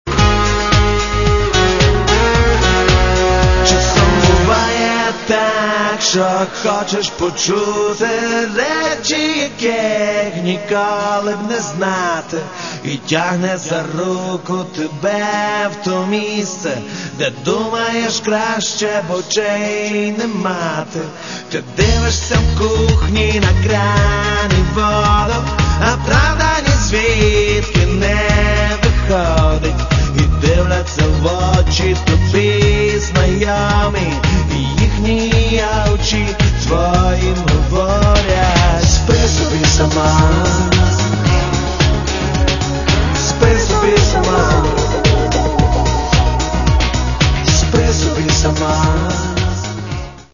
Каталог -> Рок и альтернатива -> Электронная альтернатива